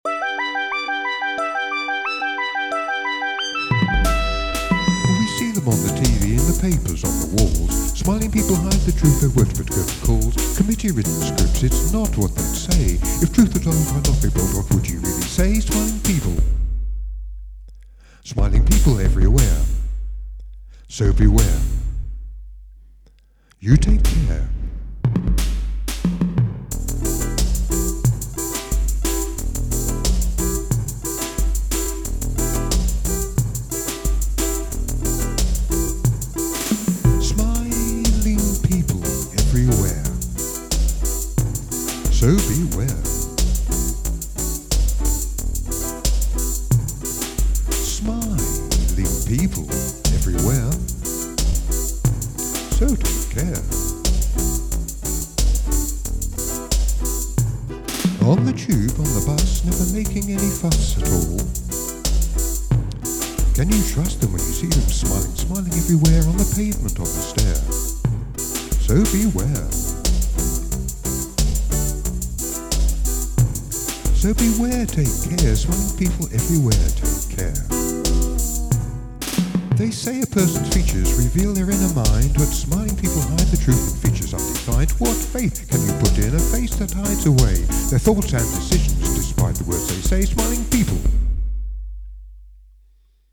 smiling-people-vocal-1.mp3